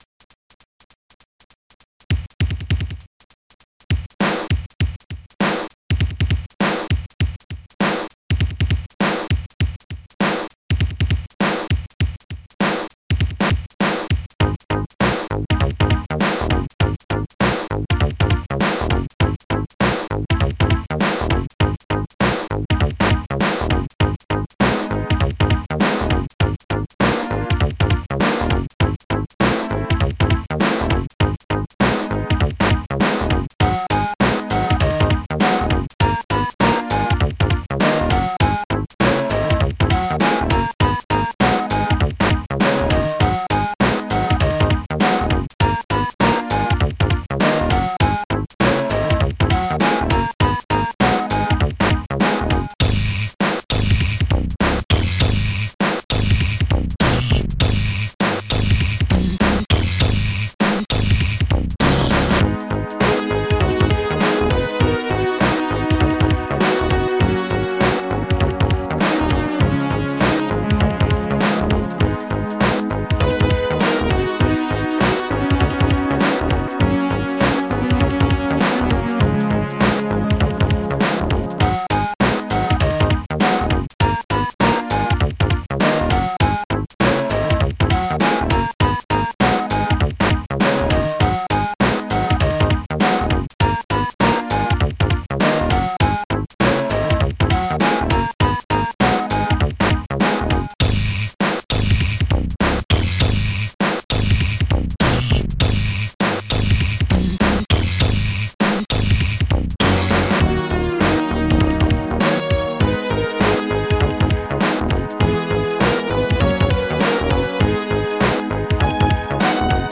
st-10:bassguitar
st-10:fleet-snare1
st-10:hihat20
st-10:fbassdrum
st-09:panpipe
st-09:longsynth